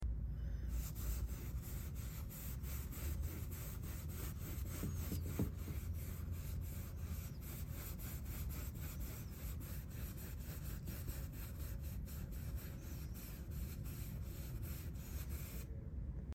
Turn up your volume for that brush stroke sound effect.